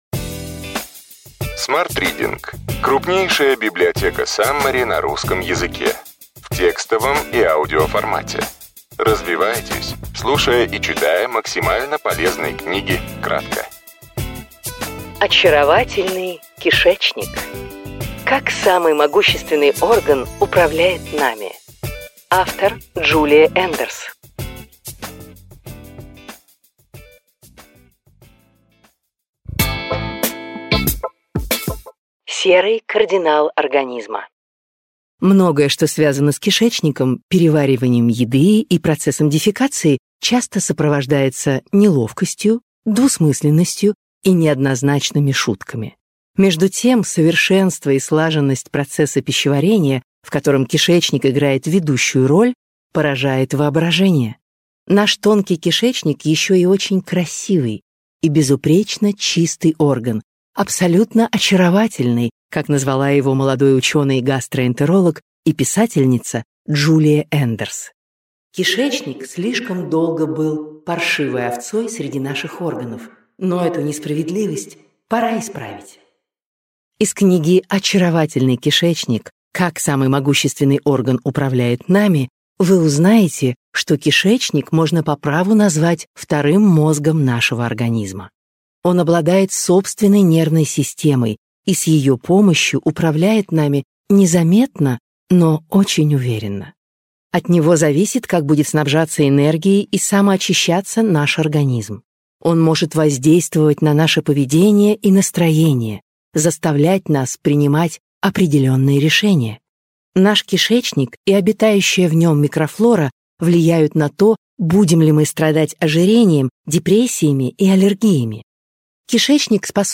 Аудиокнига Ключевые идеи книги: Очаровательный кишечник. Как самый могущественный орган управляет нами.